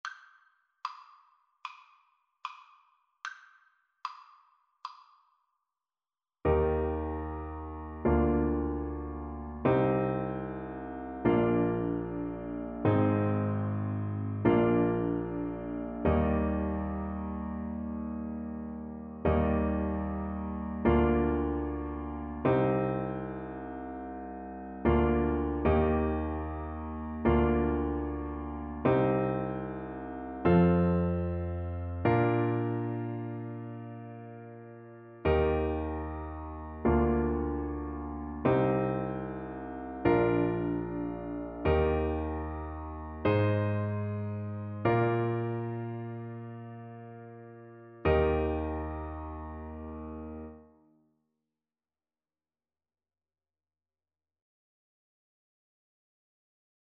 Christian
Alto Saxophone
4/4 (View more 4/4 Music)
Traditional (View more Traditional Saxophone Music)